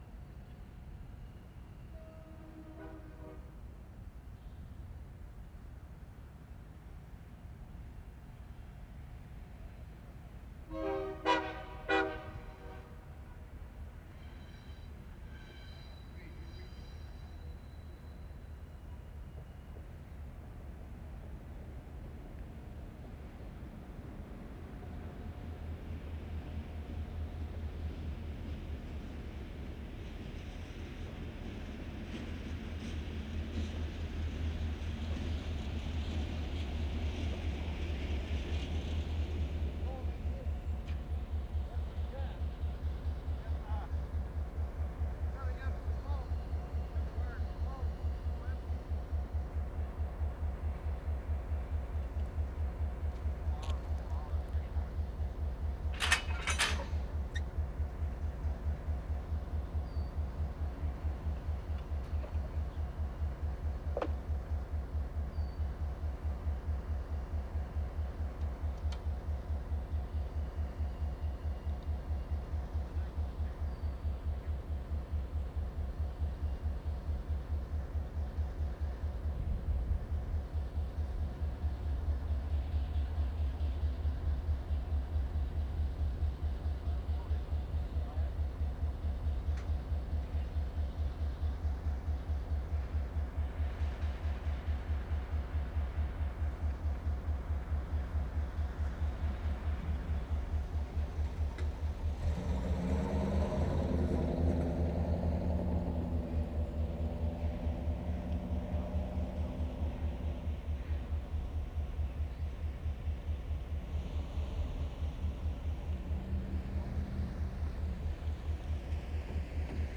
WORLD SOUNDSCAPE PROJECT TAPE LIBRARY
FALSE CREEK, train 2'15"
1. 0'05" train whistle at a distance, short but snappy.
0'15" train whistle, low frequency throb of train. Quite good.